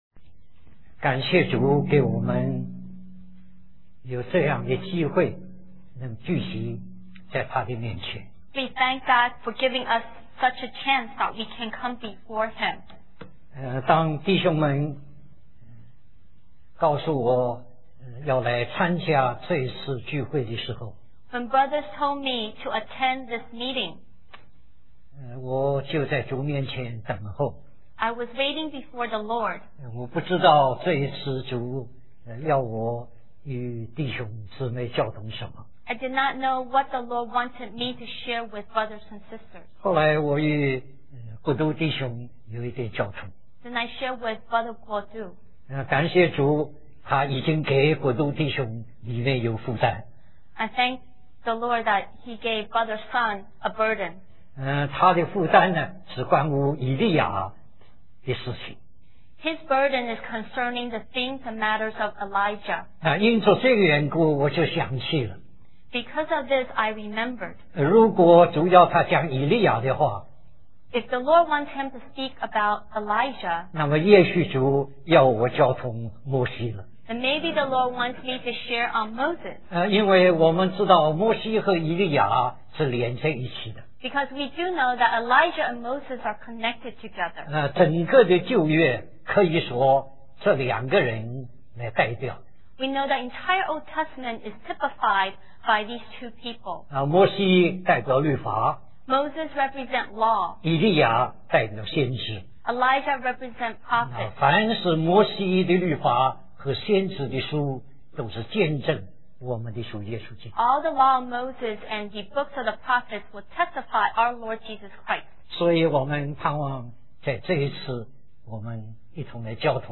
New Jersery Conference
This messages is in both languages.